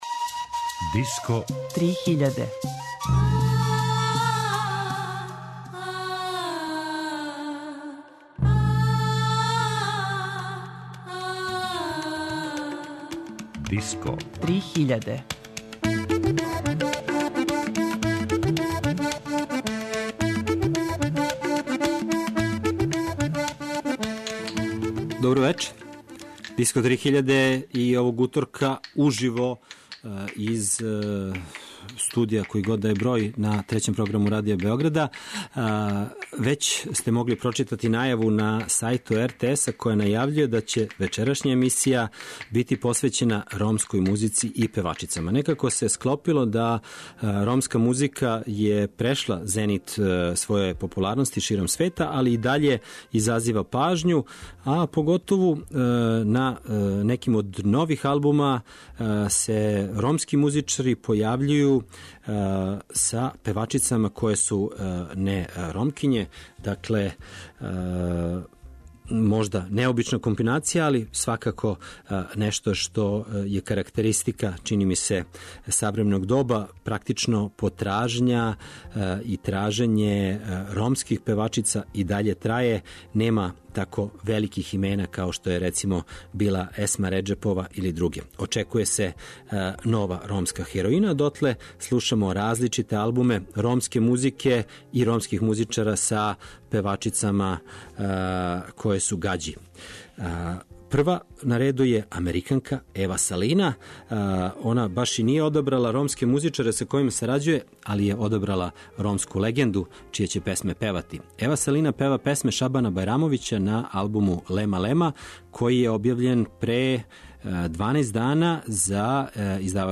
Disco 3000 – Ромска музика и женски гласови